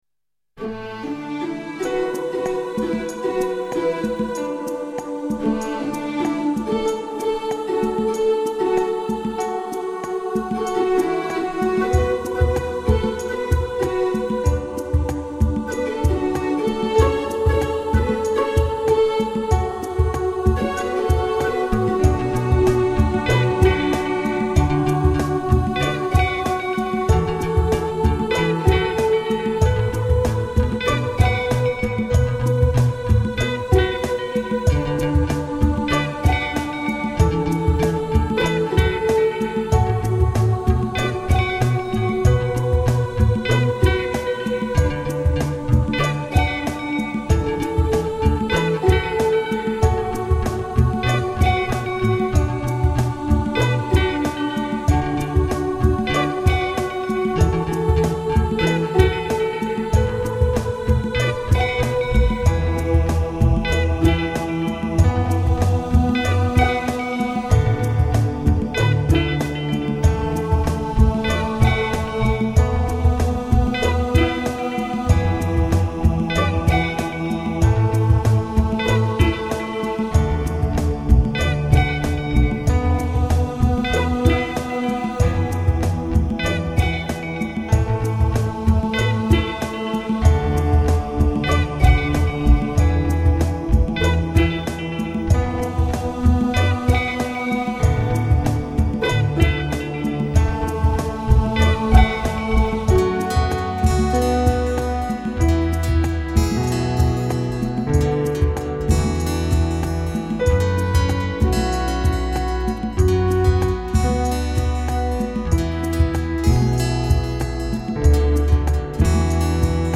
I´m happy to introduce my new original instrumental song